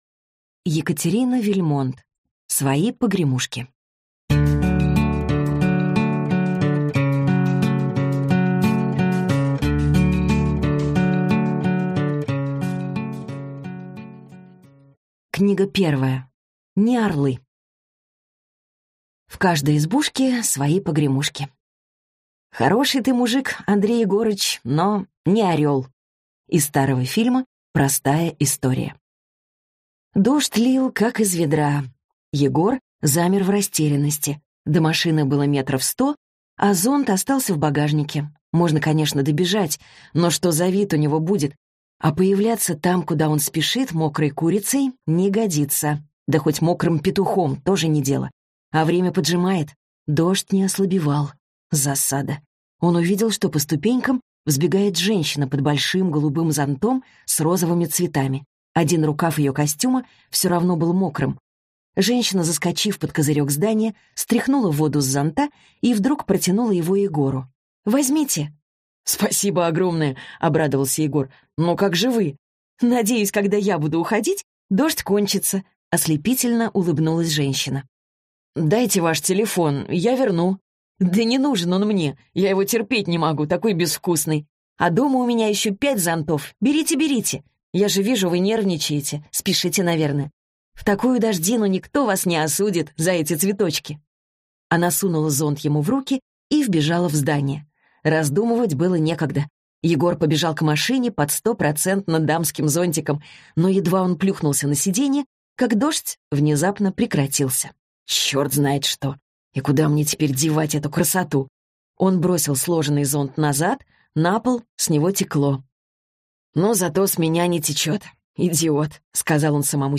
Аудиокнига Свои погремушки - купить, скачать и слушать онлайн | КнигоПоиск